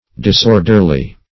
Disorderly \Dis*or"der*ly\, adv.